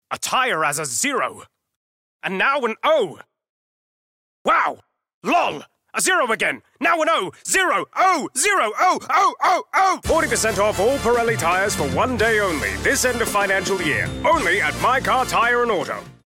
Male
English (North American), English (Australian)
Yng Adult (18-29), Adult (30-50)
Energetic, Bubbly, mid ranged, Friendly, guy next door type.
A natural and friendly voice that brings both calm and energy to reads
My Car Character Tv Spot